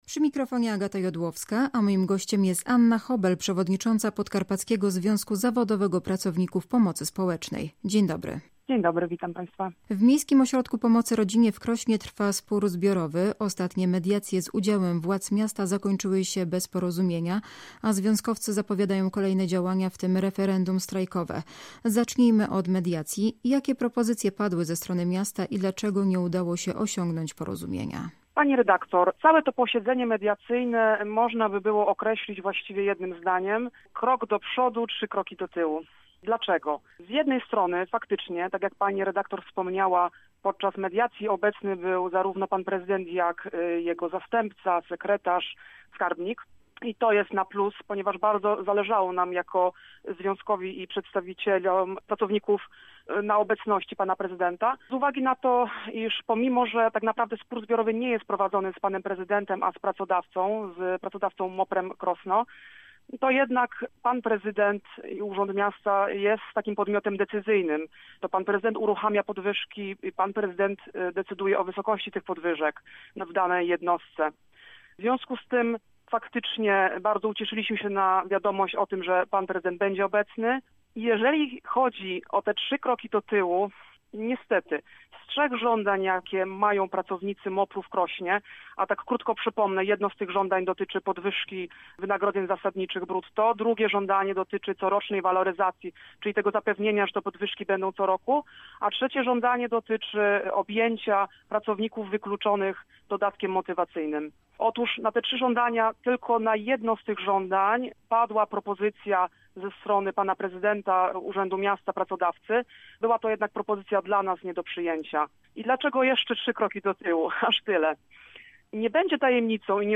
Całej rozmowy można posłuchać tutaj: